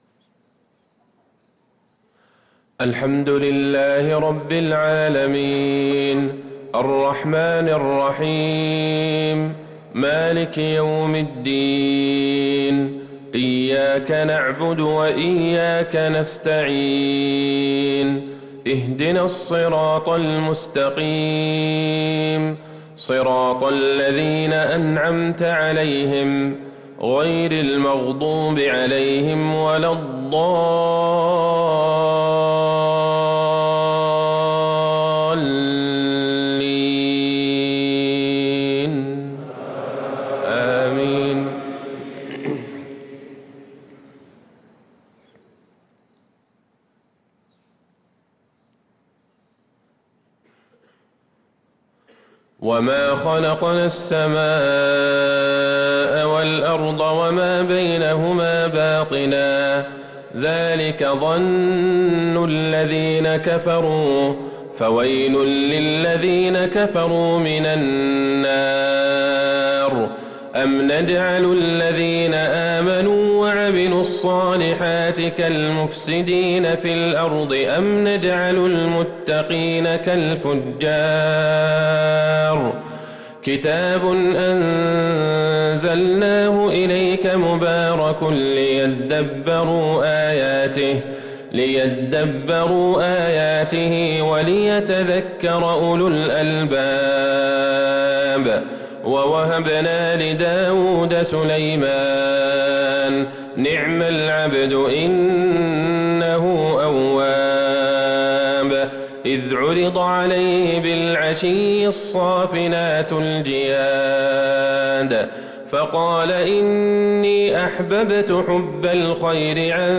صلاة الفجر للقارئ عبدالله البعيجان 24 محرم 1444 هـ
تِلَاوَات الْحَرَمَيْن .